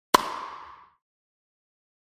テニスボールを突く音 着信音
テニスボールがラケットや壁などに当たったときの音。やや高めの音。